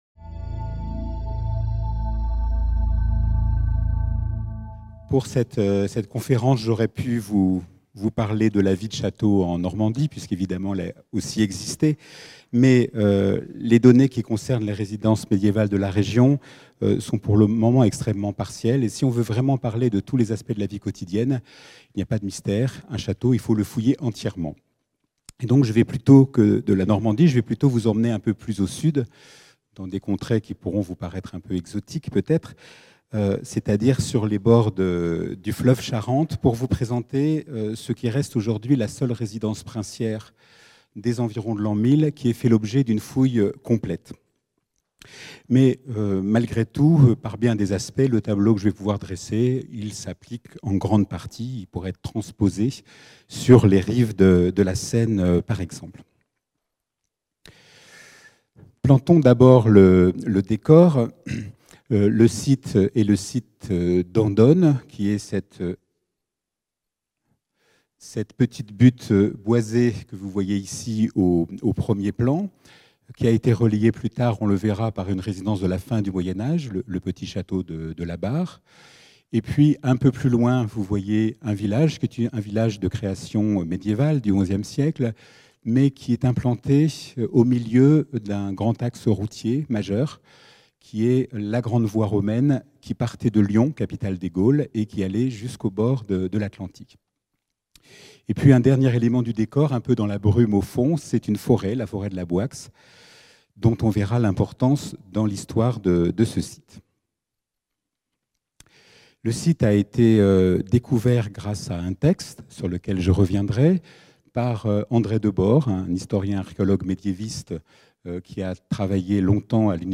La fin du Xe siècle voit naître de nouvelles formes de résidences des élites qui sont à la source des châteaux médiévaux. À partir de l’exemple du castrum des comtes d’Angoulême à Andone (Charente), la seule résidence princière de cette période qui ait fait l’objet d’une fouille archéologique exhaustive, la conférence s’attachera à restituer la vie quotidienne de deux générations d’occupants, entre 975 et 1025.